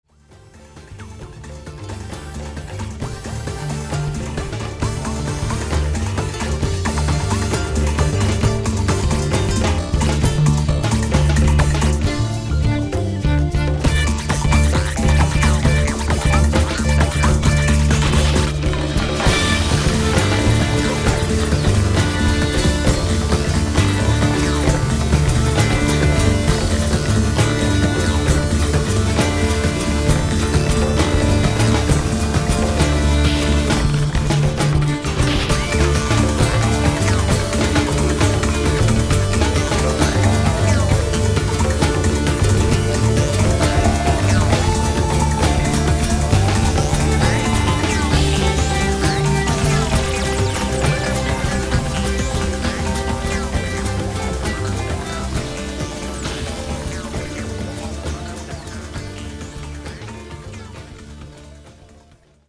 Genre: Dichotic Music.